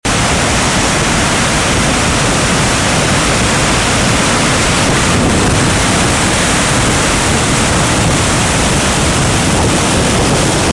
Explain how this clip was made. The most powerful waterfall in Europe - Dettifoss, Iceland 🇮🇸 it was incredible to stand next to!!!!